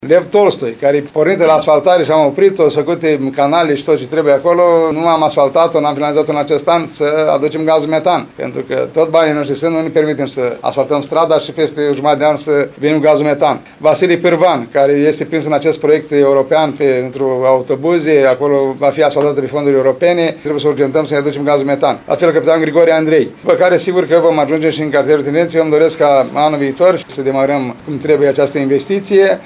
Primarul ION LUNGU a detaliat care sunt străzile pe care vor începe lucrările.